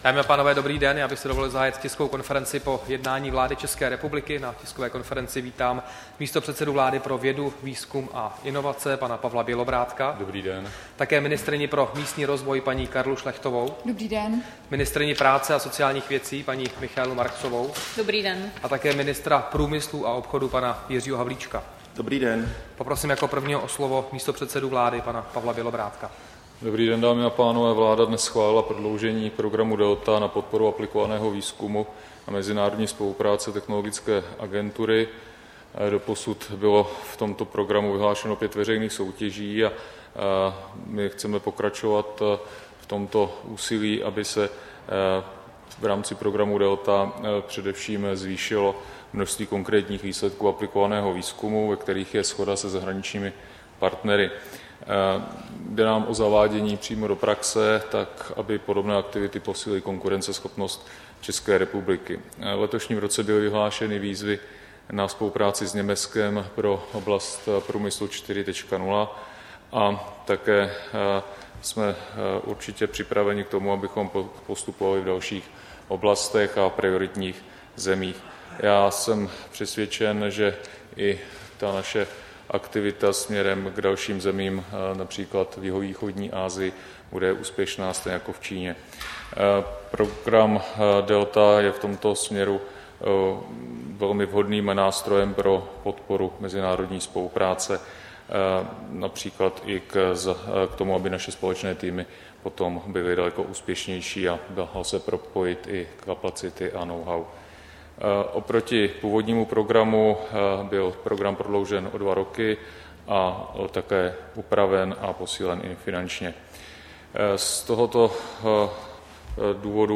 Tisková konference po jednání vlády, 18. září 2017